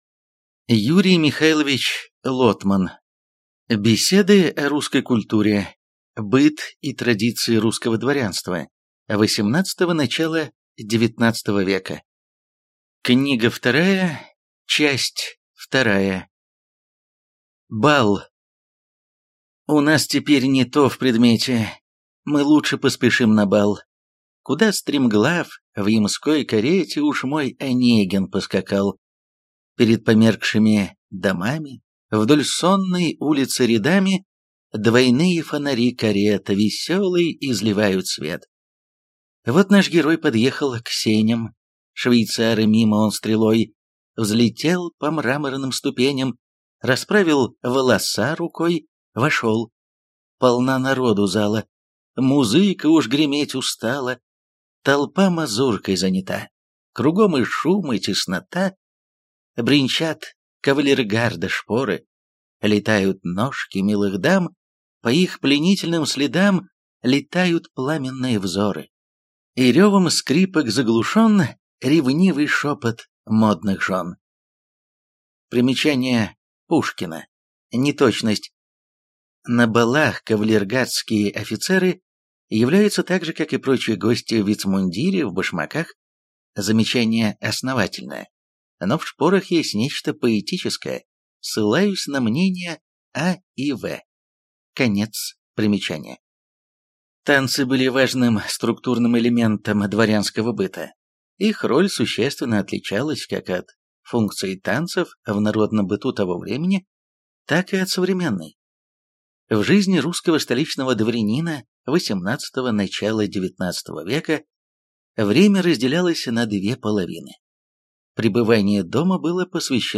Аудиокнига Беседы о русской культуре: Быт и традиции русского дворянства (XVIII – начало XIX века) (Книга 2) | Библиотека аудиокниг